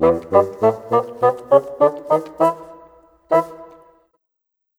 Rock-Pop 20 Bassoon 02.wav